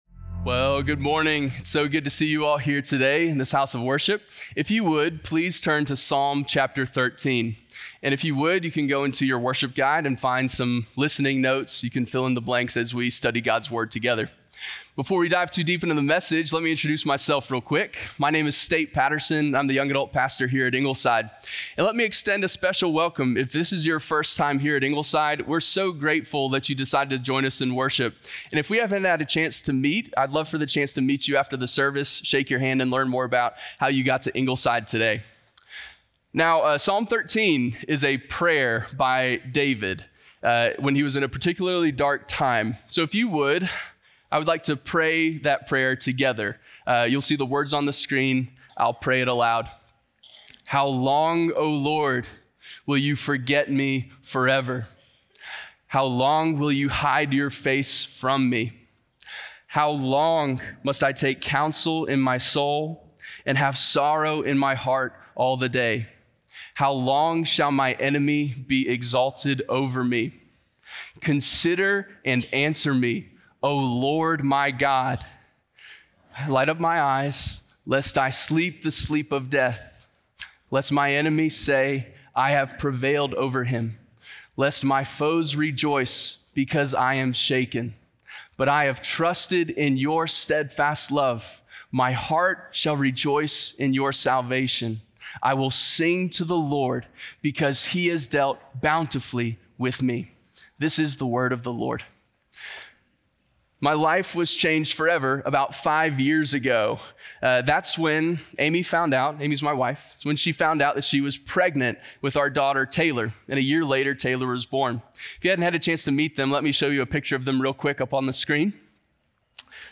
The Language of Lament - Sermon - Ingleside Baptist Church